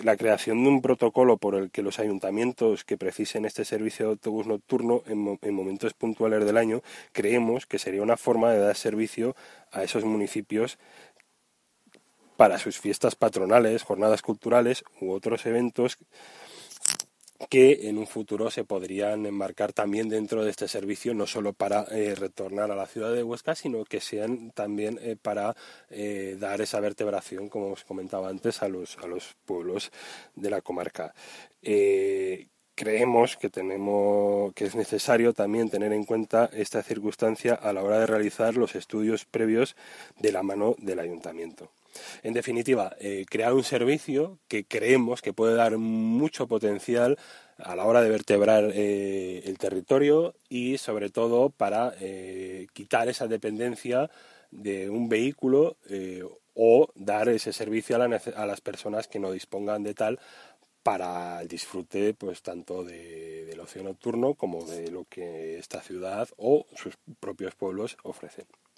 Audios de Enrique Novella, portavoz de C’s en la Comarca La Hoya de Huesca: